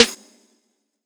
GDH_SNR.wav